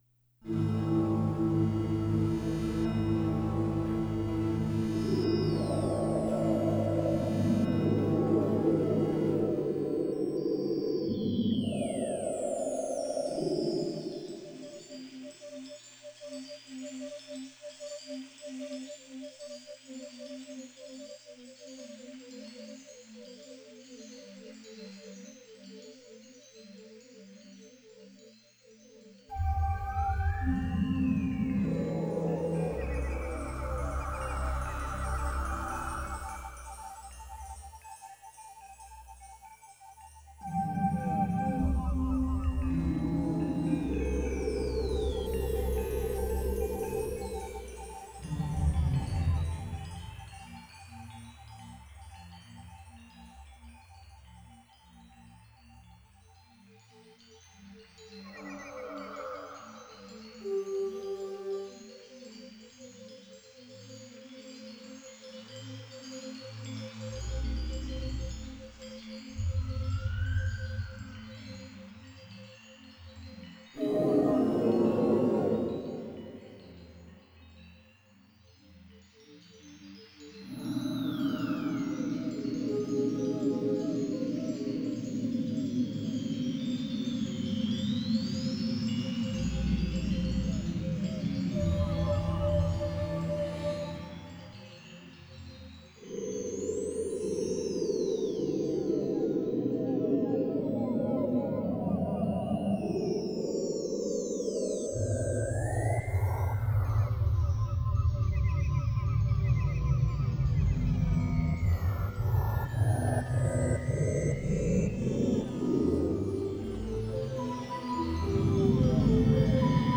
Sound Effects Three Synthesizers